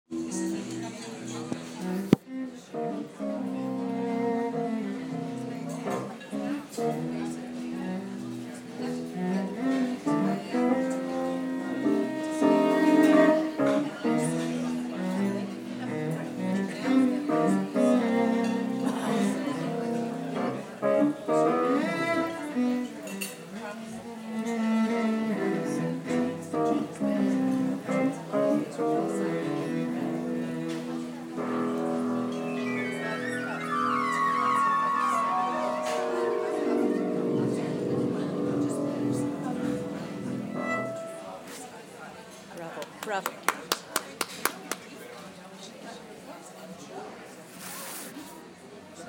Croatian Cello Piano